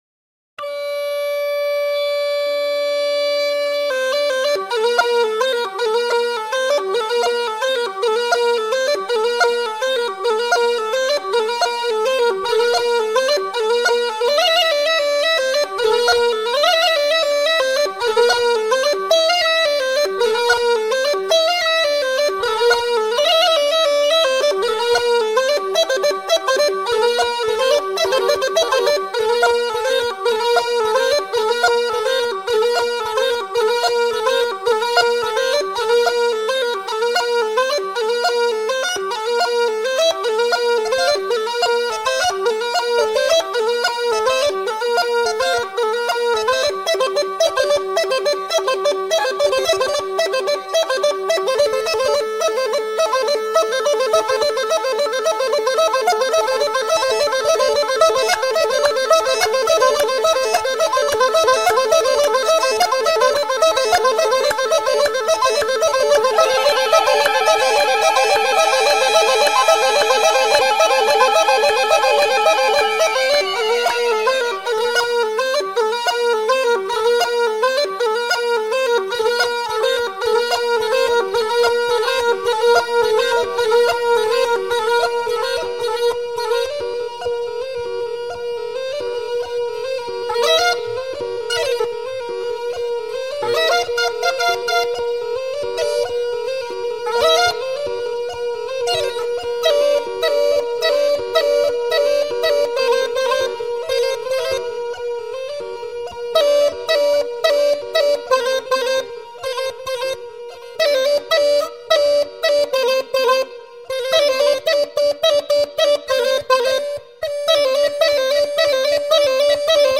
تمپو ۱۰۸ دانلود